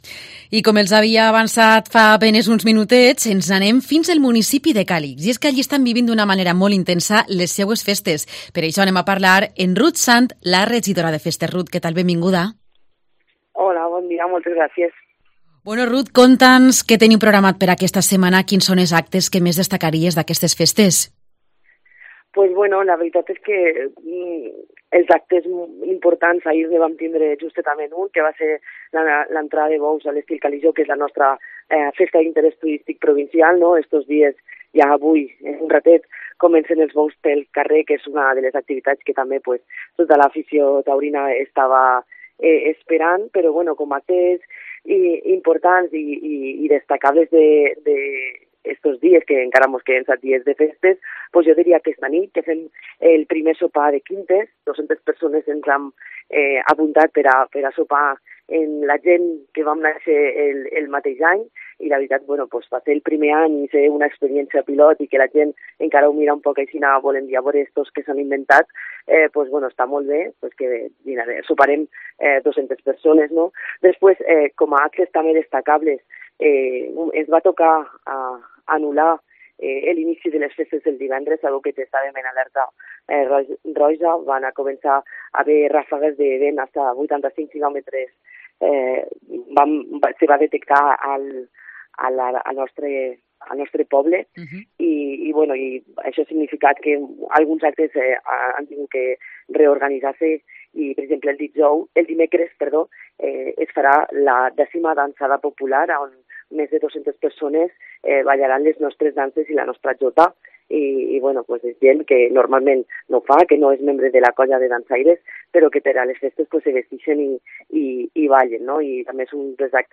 La edil de fiestas da cuenta en Cope de todos los actos programados.